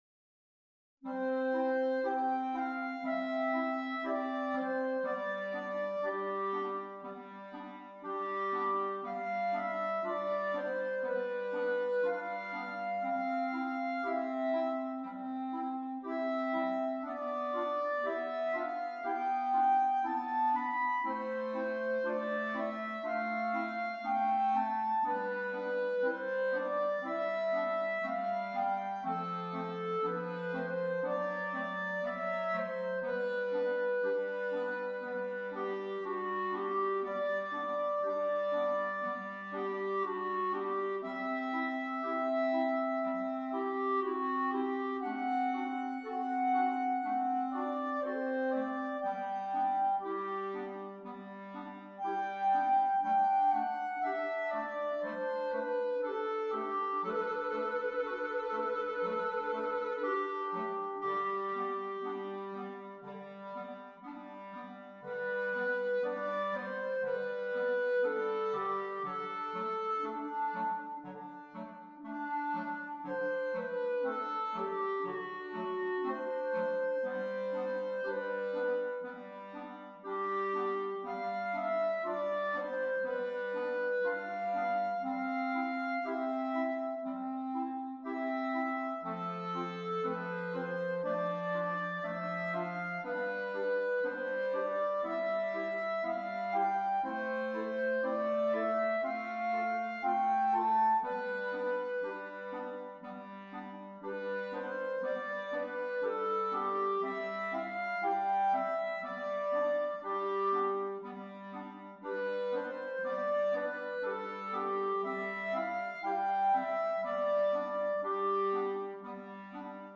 4 Clarinets